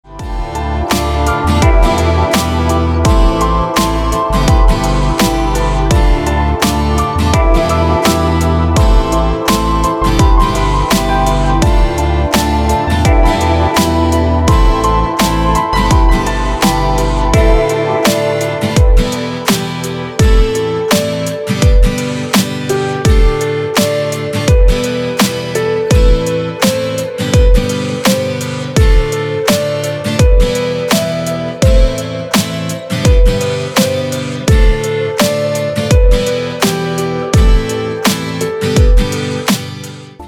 • Качество: Хорошее
• Песня: Рингтон, нарезка
• Категория: Рингтоны